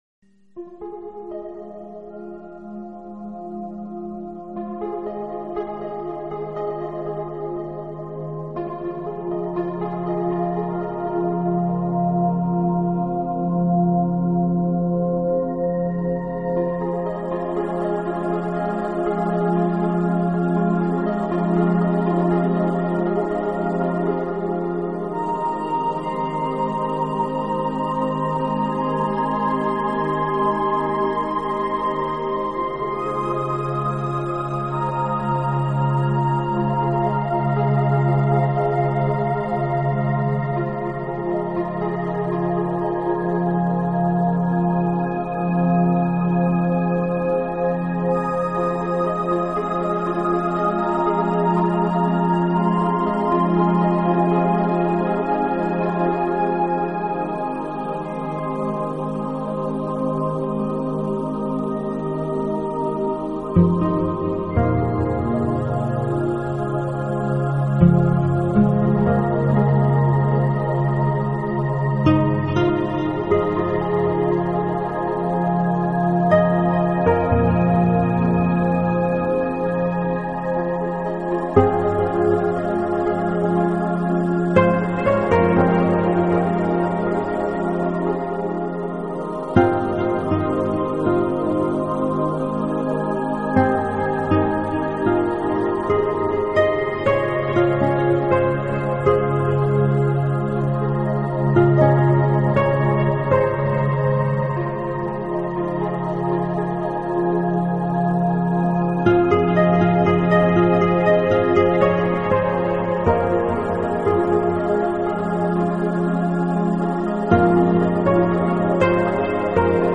大部分作品偏向于“Healing有治疗功用的、康复中的”音乐，出品过“Mind智